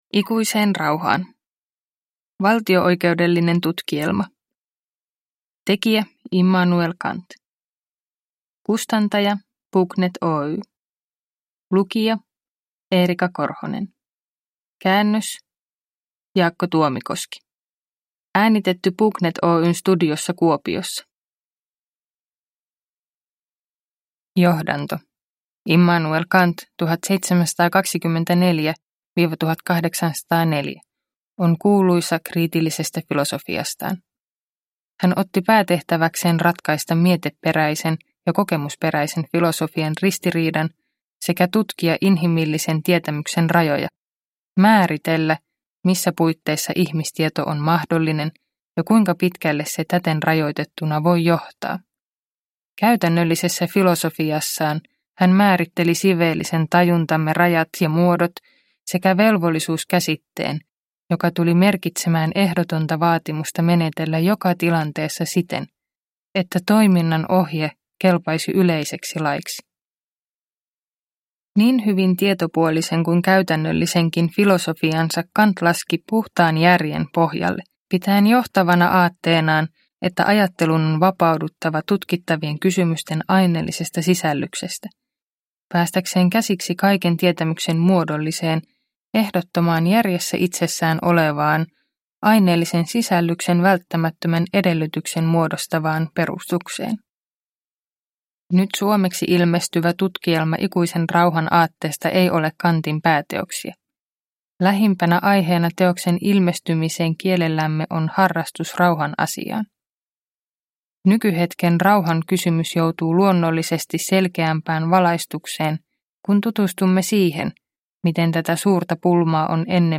Ikuiseen rauhaan – Ljudbok